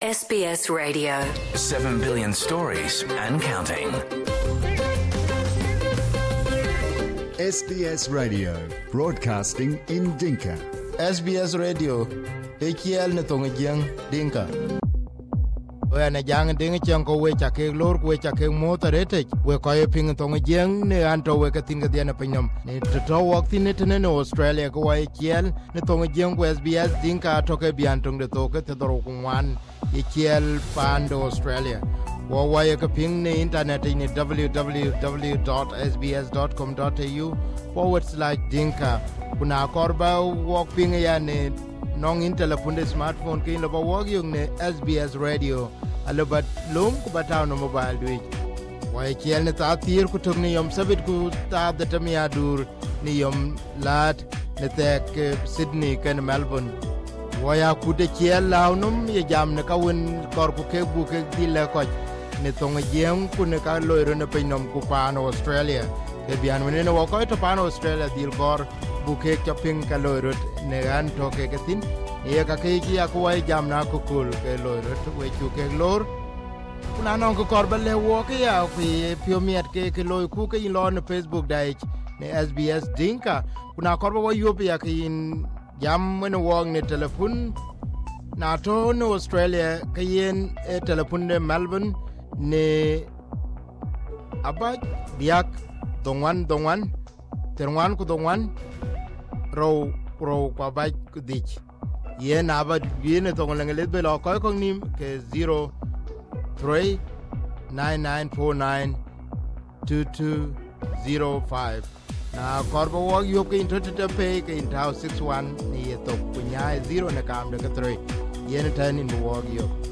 SBS Dinka conducted this exclusive interview with Dr Lam while he was still under house arrest.